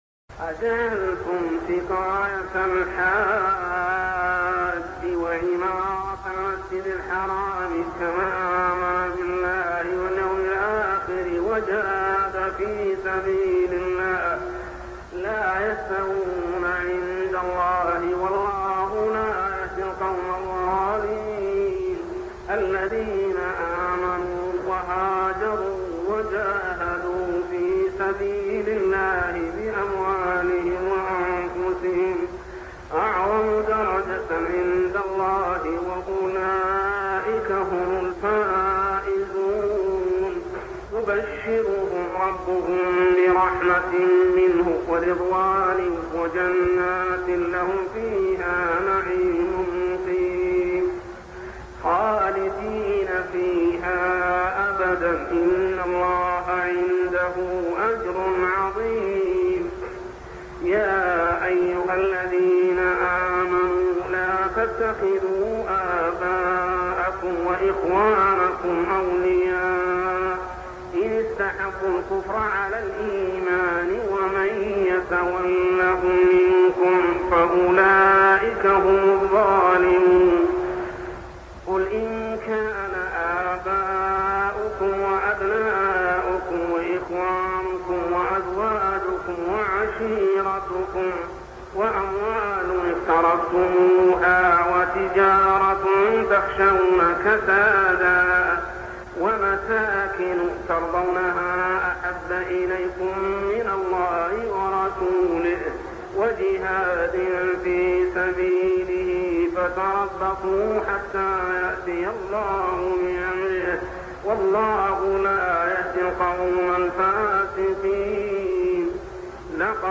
صلاة التهجد عام 1401هـ سورة التوبة 19-93 ( من الآية 34 حتى الآية 45 مفقودة ) | Tahajjud prayer Surah At-Tawbah > تراويح الحرم المكي عام 1401 🕋 > التراويح - تلاوات الحرمين